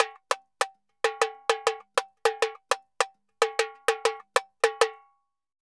Tamborin Salsa 100_3.wav